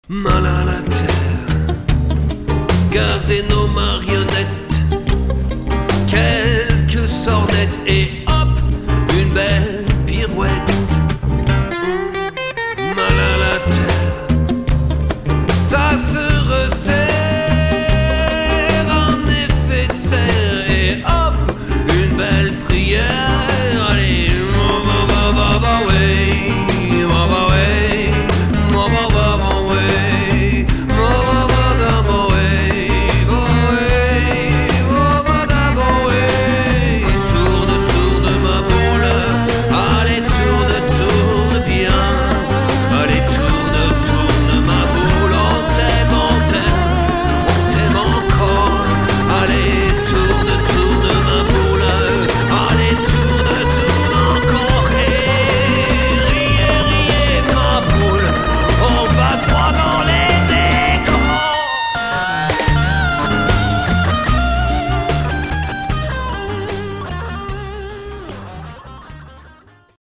guitare électrique, contrebasse et batterie